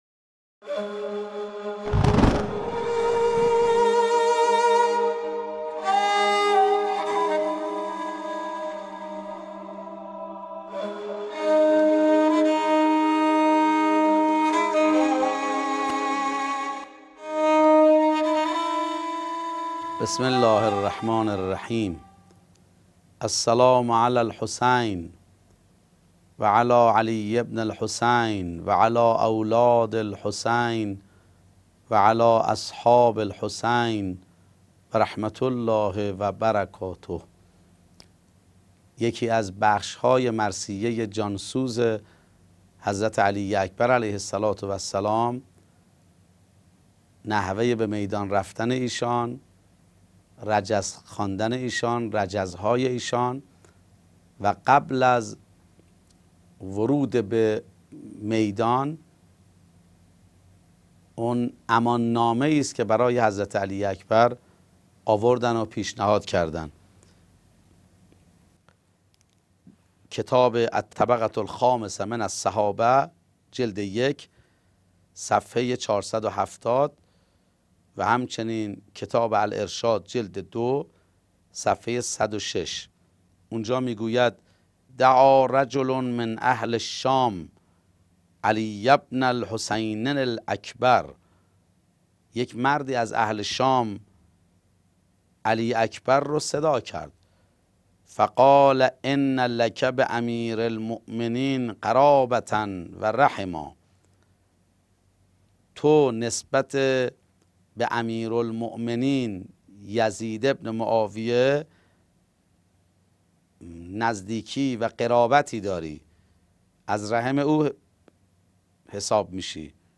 سلسله گفتارهایی پیرامون تبارشناسی عاشورا